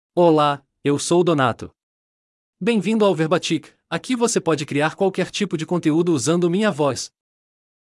MalePortuguese (Brazil)
DonatoMale Portuguese AI voice
Voice sample
Male
Donato delivers clear pronunciation with authentic Brazil Portuguese intonation, making your content sound professionally produced.